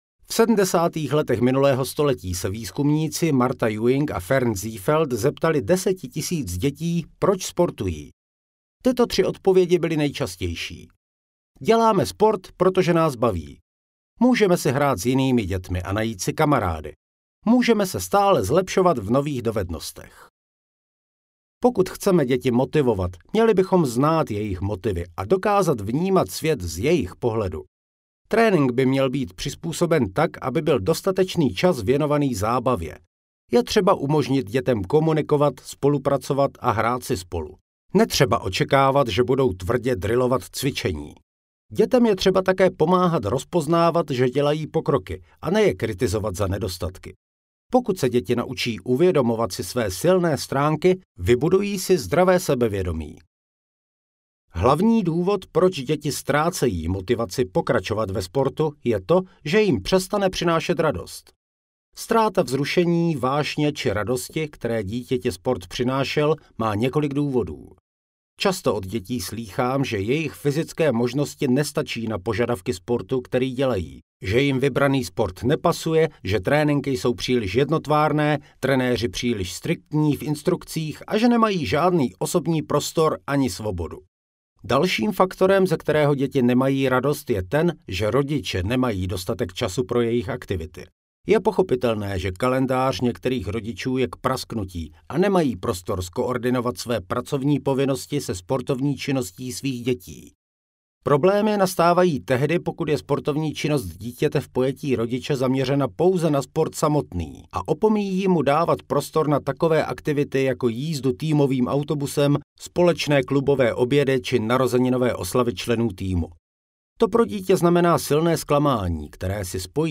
Audiokniha Bez strachu - Adam Truhlář | ProgresGuru
Poslechnout delší ukázku